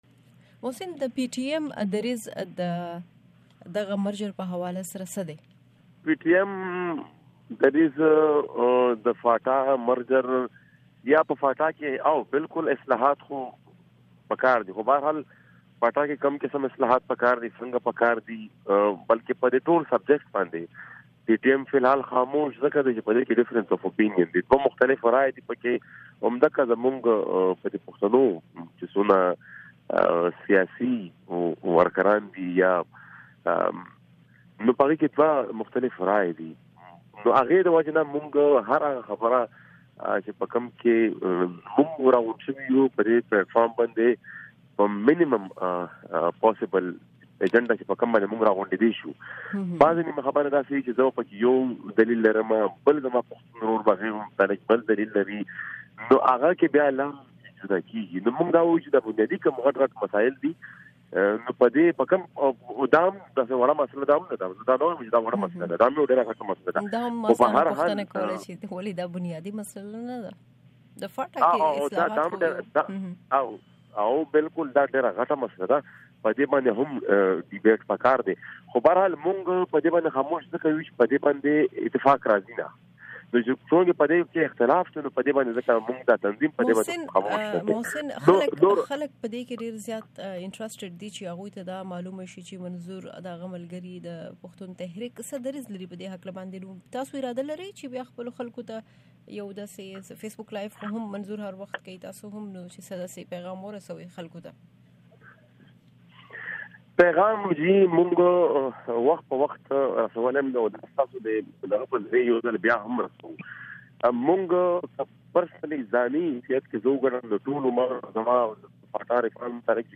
پام وکړۍ د پښتون ژغورنې د تحریک د یو مشر محسن داوړ سره زمونږ مرکې ته
د محسن داوړ مرکه